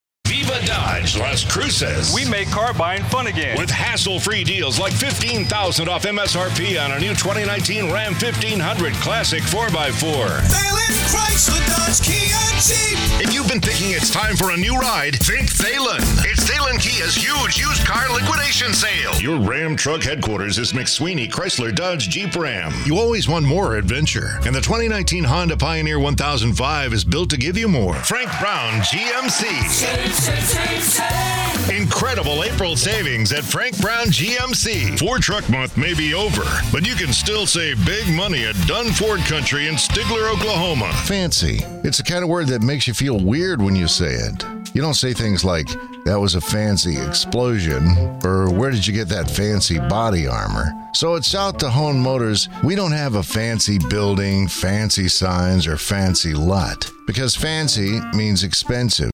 Smooth, warm, conversational, authentic and inviting, yet capably diverse
Authentic Texan
Middle Aged
I have a great home studio with Neumann mic, Pro Tools, isolated vocal booth and Source Connect!!